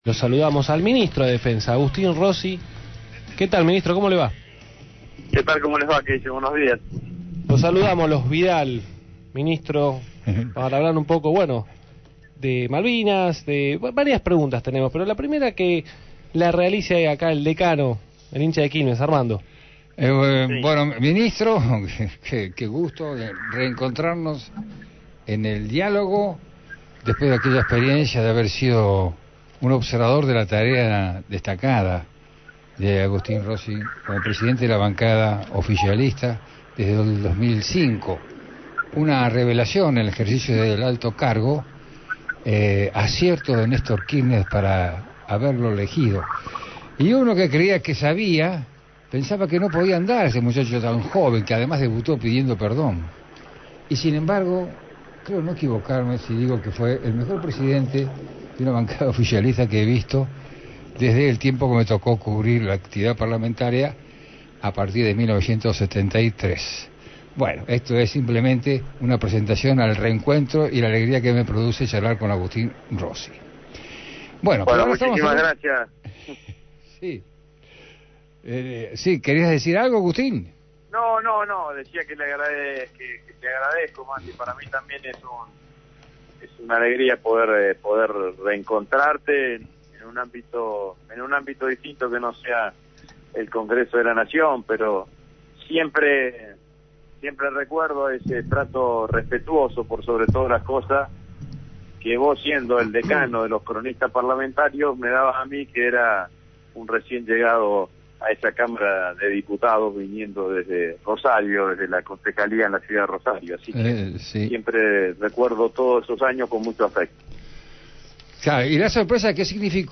Agustín Rossi, ministro de Defensa de la Nación, mantuvo una entrevista en el programa De Acá para Allá, por la Gráfica. La desclasificación de documentos sobre la guerra de Malvinas, los movilizados que acampan en Plaza de Mayo y si debe regresar o no lo que se conocía como el servicio militar obligatorio, fueron los ejes del reportaje.